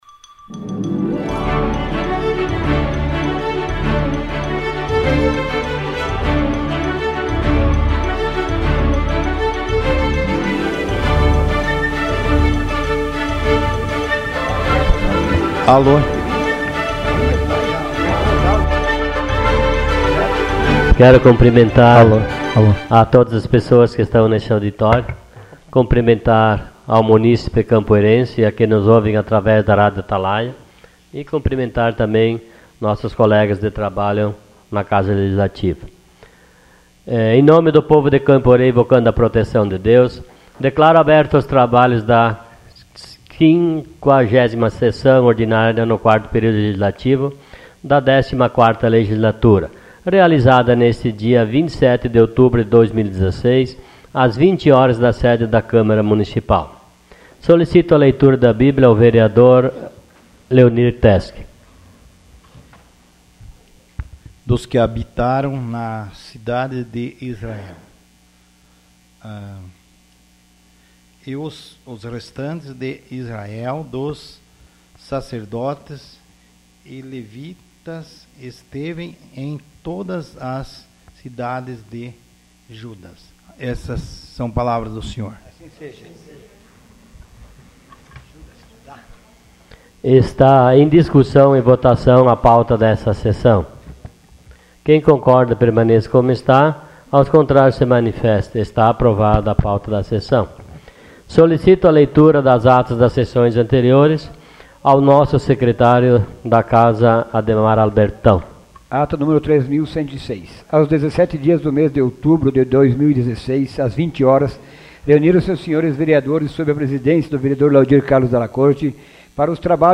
Sessão Ordinária dia 27 de outubro de 2016.